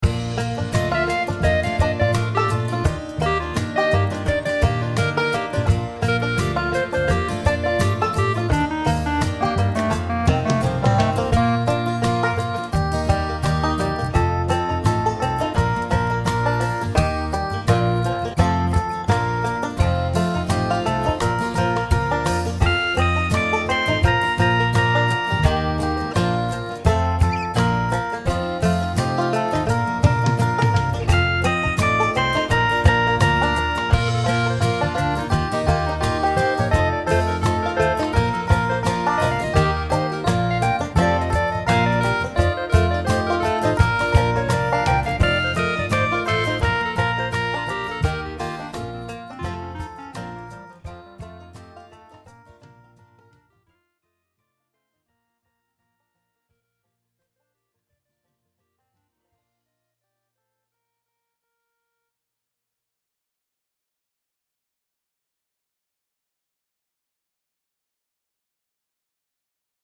Maza dziesmiņa Play-along.
Spied šeit, lai paklausītos Demo ar melodiju